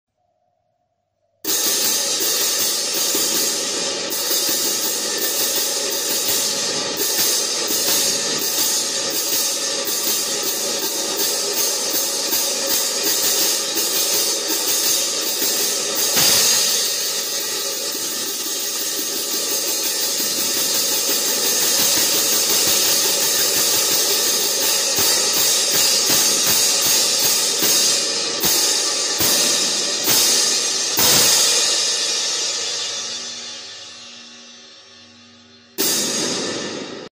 Percusión
PLATILLOS
platillos_de_choq.mp3